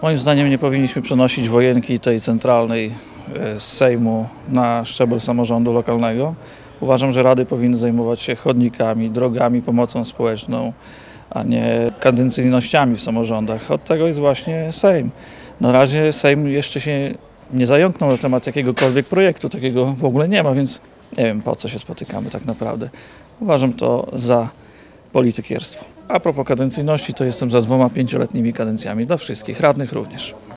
Nie wszyscy radni byli za przyjęciem stanowiska do pomysłów które – przynajmniej na razie – funkcjonują tylko w przekazach medialnych.  Powinniśmy zajmować się sprawami dla nas najbliższymi – mówi Michał Tyszkiewicz, radny miejski.